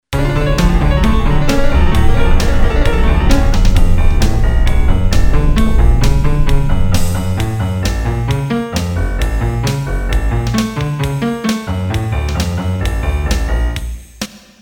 It could be used as a Mission Impossible or The Untouchables theme. The algorithm is based on fractal geometry - a Mandelbrot fractal set.